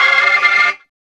0506L ORGCHD.wav